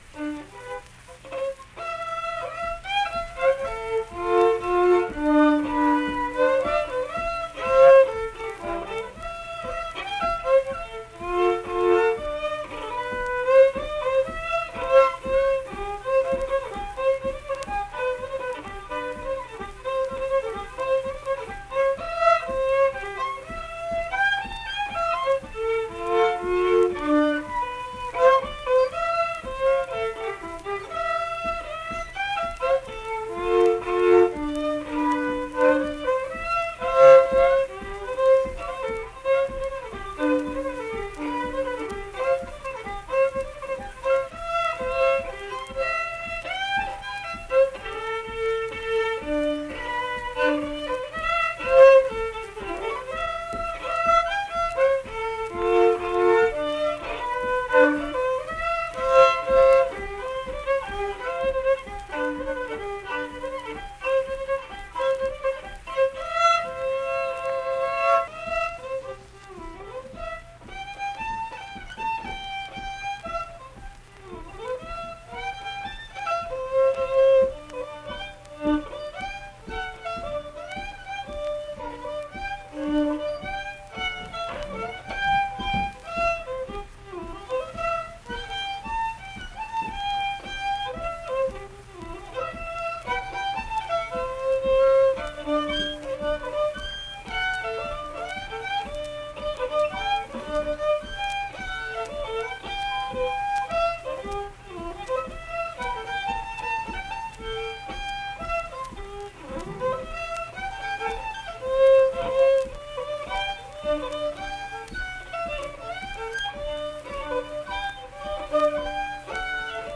Unaccompanied fiddle performance
Instrumental Music
Fiddle
Middlebourne (W. Va.), Tyler County (W. Va.)